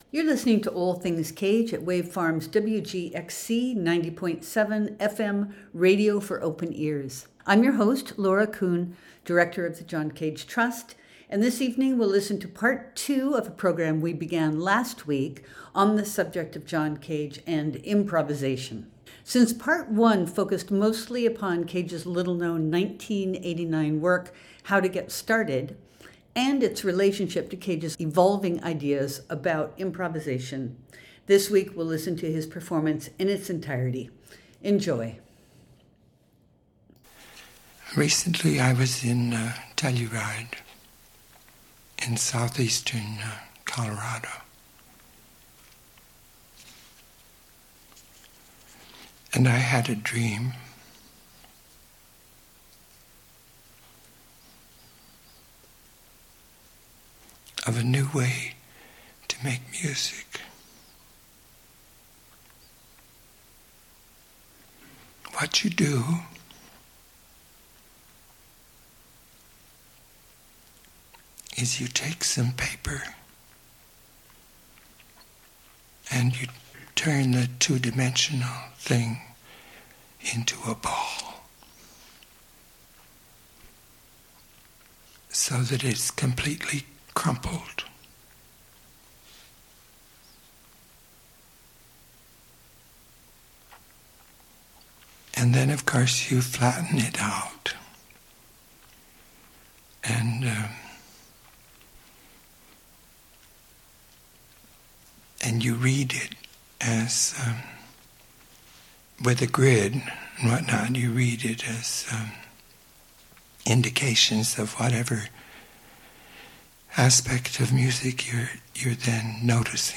For his new work, Cage proposed a collaborative framework in which sound engineers would capture and subsequently layer his extemporized monologue, which consisted of ten improvised commentaries on topics of interest. This was an experiment in improvisation, to be sure, but it was also an experiment in thinking in public, before a live audience.